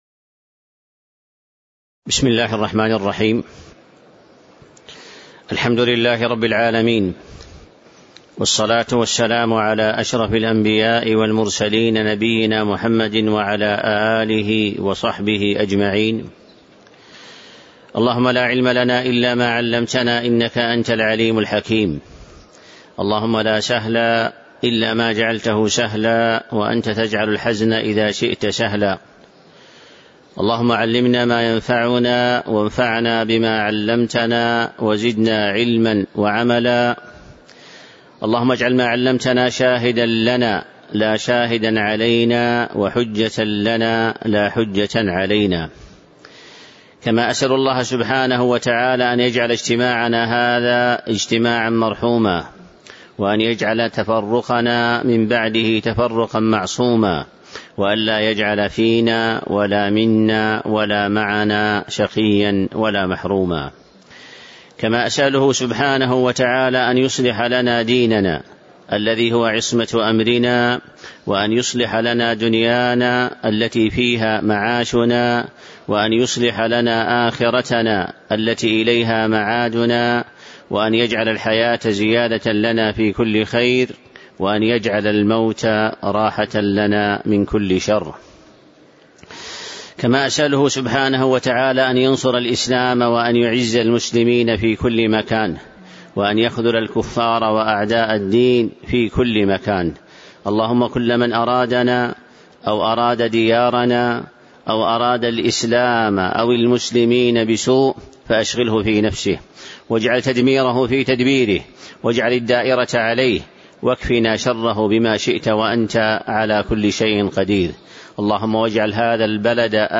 تاريخ النشر ١٧ ربيع الثاني ١٤٤٣ هـ المكان: المسجد النبوي الشيخ